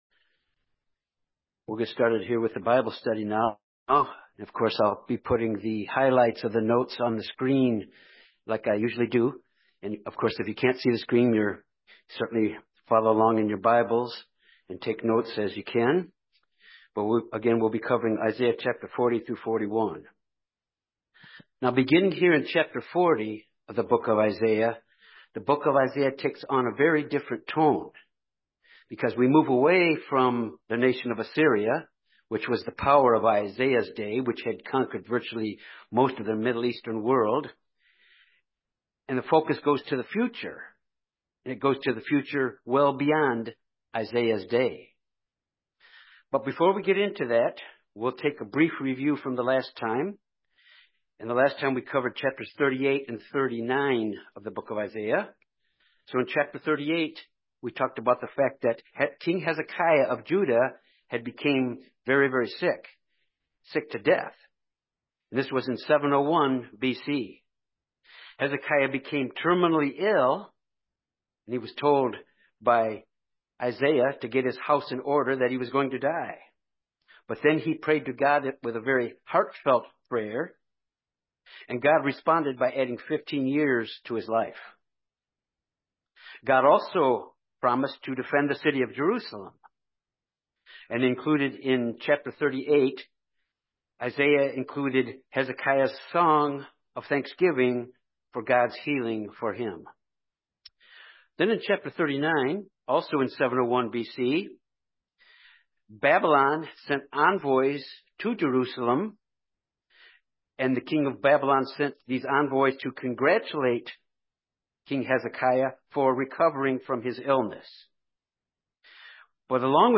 Sermons
Given in Jonesboro, AR Little Rock, AR Memphis, TN